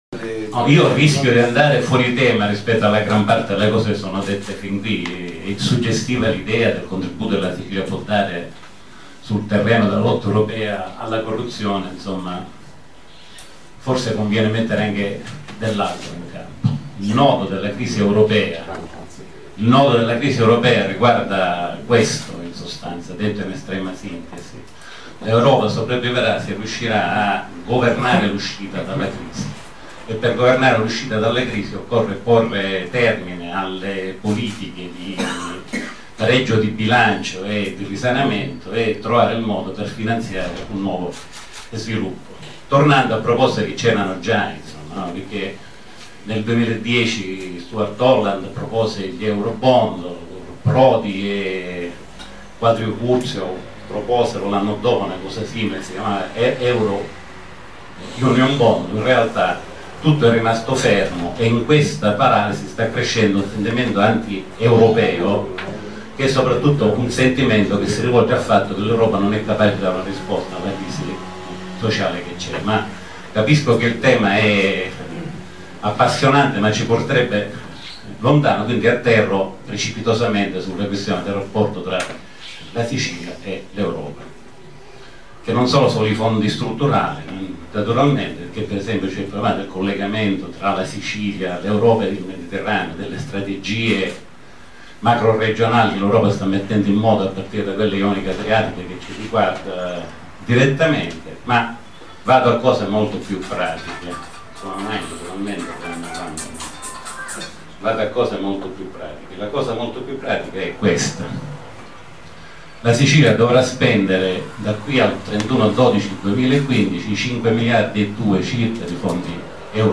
FORUM CON I CANDIDATI ALLE ELEZIONI EUROPEE 2014
CENTRO STUDI PIO LA TORRE
PALERMO